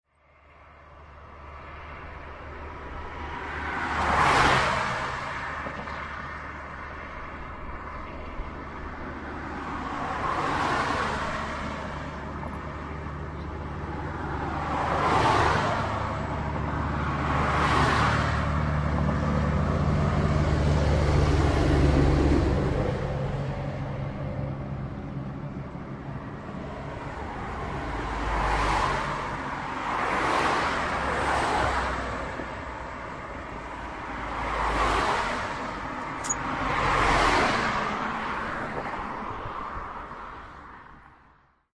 没有人，有很多车。
Tag: 音景 汽车 交通 噪音 公交车 博洛尼亚 现场录音 户外